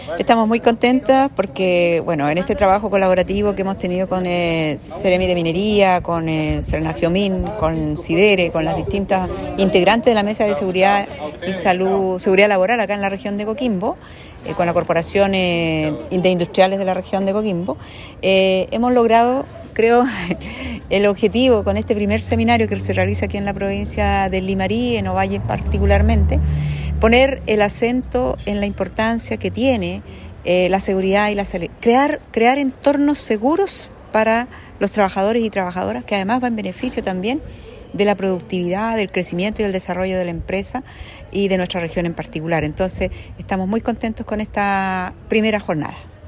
La Seremi del Trabajo, Claudia Santander, expresó que